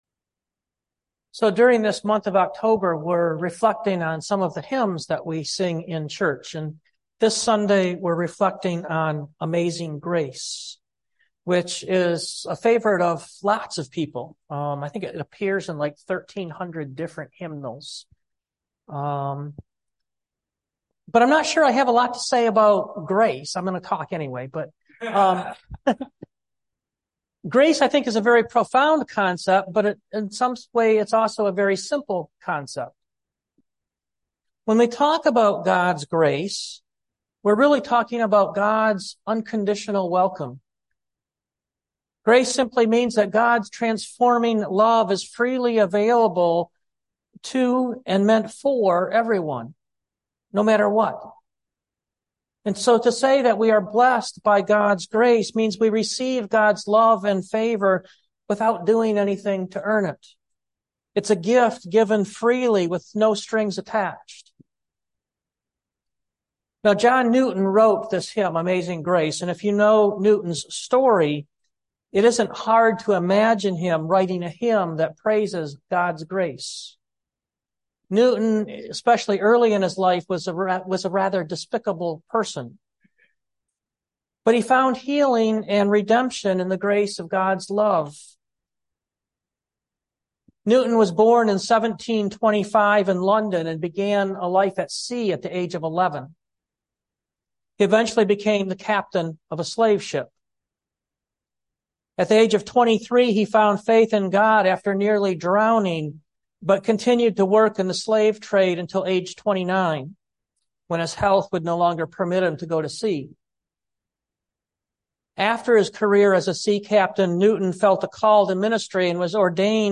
2023 Amazing Grace Preacher